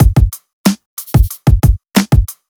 FK092BEAT1-R.wav